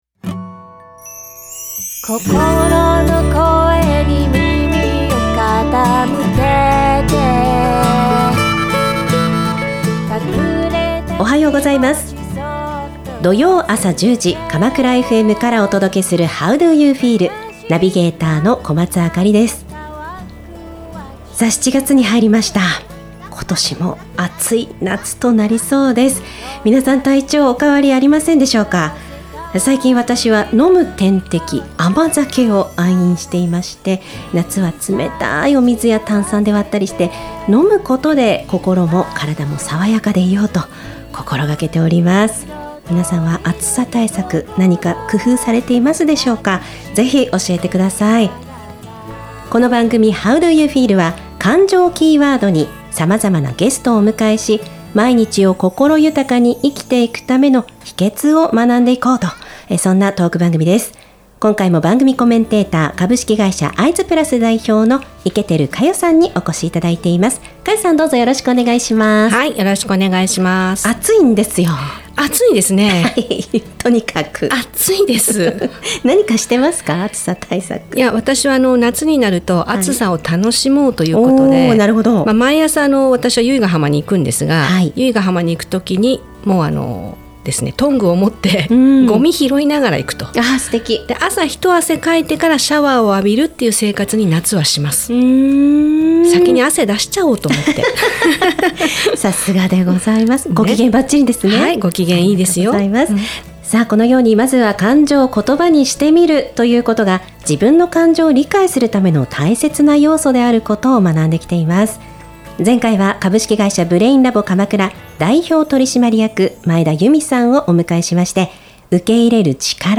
番組では「感情知性=EQ」に注目!自身の感情を見つめ、心を豊かにするヒントを学んでいく、ゲストトーク番組です。